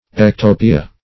Ectopia \Ec*to"pi*a\, n. [NL., fr. Gr.